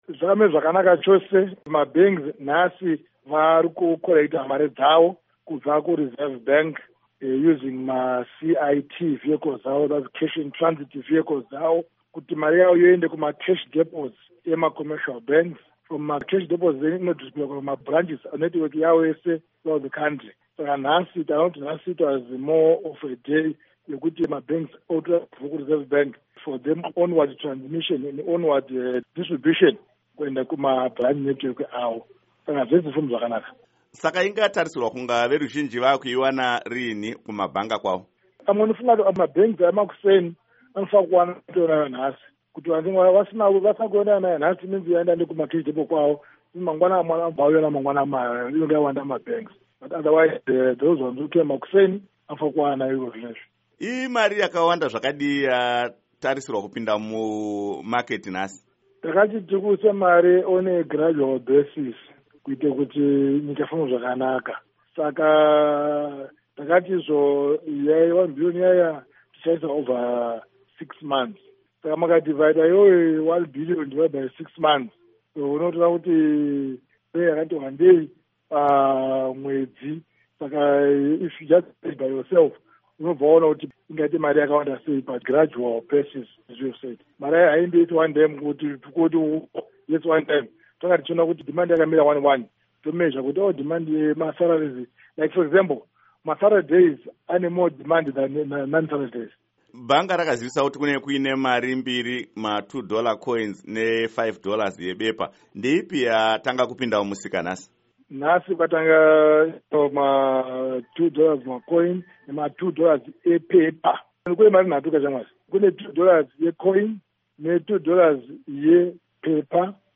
Hurukuro naDoctor John Mangudya